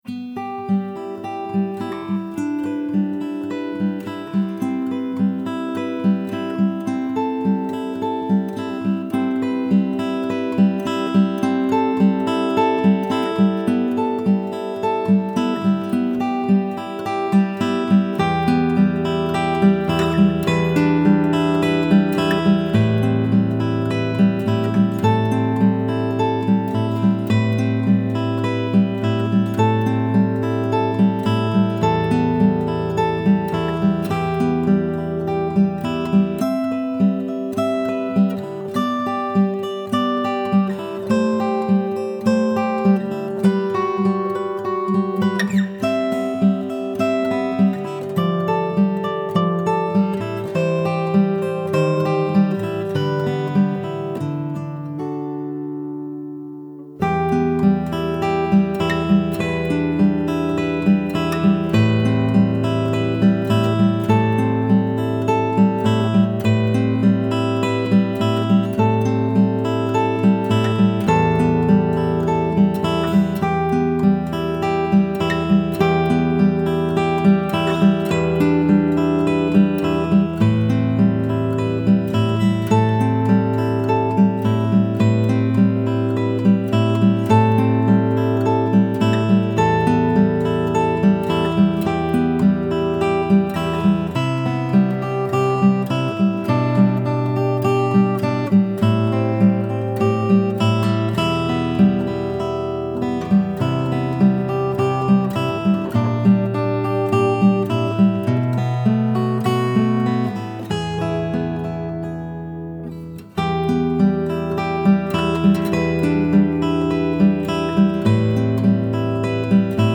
But then something extraordinary happened – I heard beautiful chords on my guitar.
I recorded my song in progress and it can be heard with the blue link below:
Guitar Instrumental New Song in Progress – 9/1/16
guitar-piece-8-1-16-mix-3.mp3